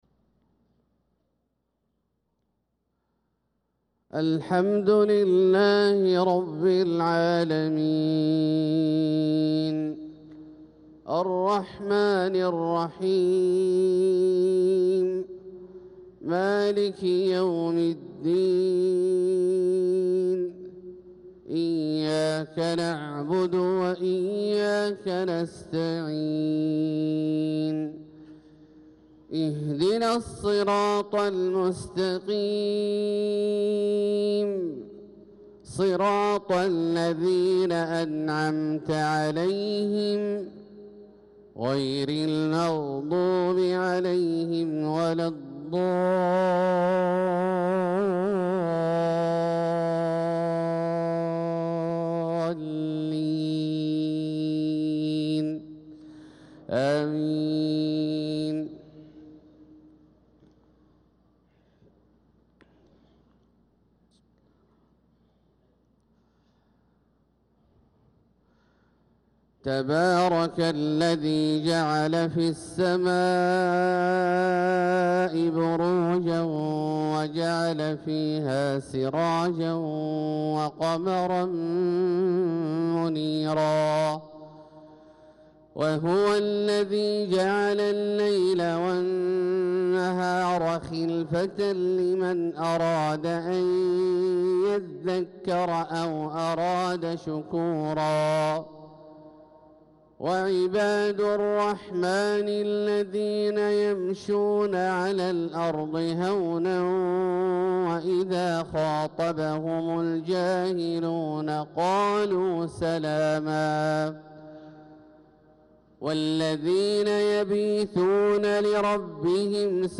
صلاة الفجر للقارئ عبدالله الجهني 23 ذو الحجة 1445 هـ